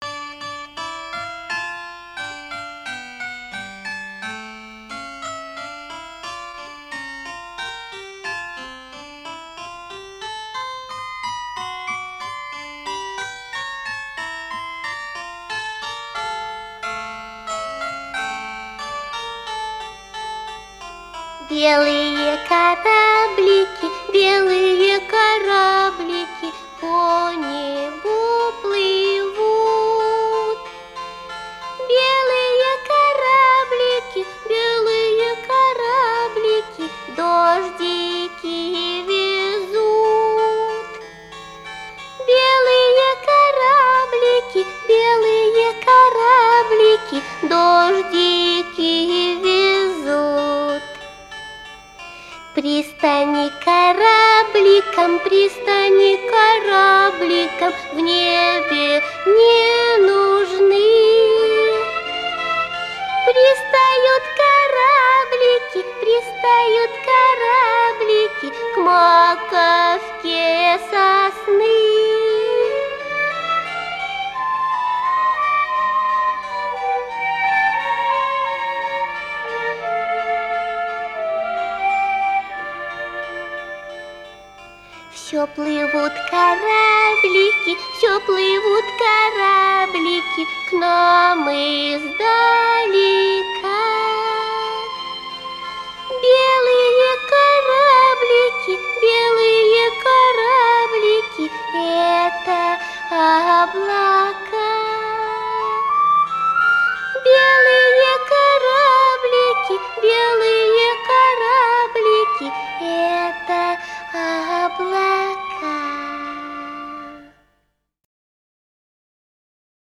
Трепетная детская песенка
наивное исполнение тоненьким голоском
прекрасная задушевная музыка